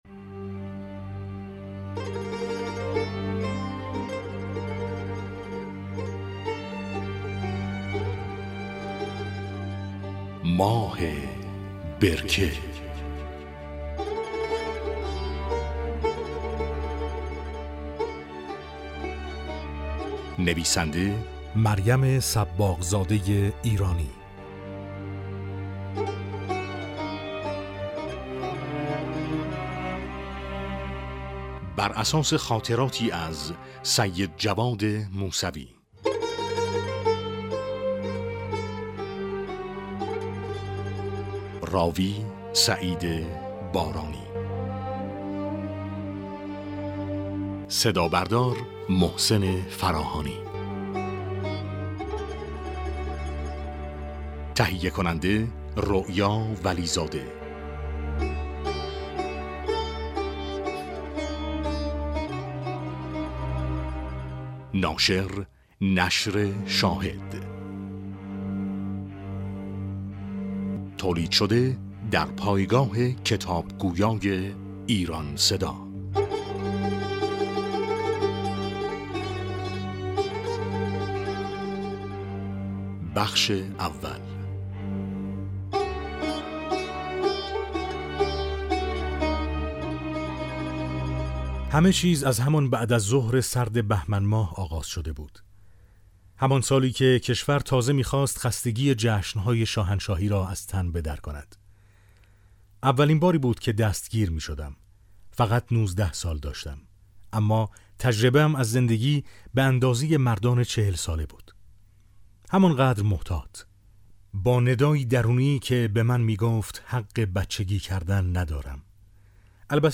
امکان دانلود رایگان کتاب صوتی «ماه برکه» فراهم شد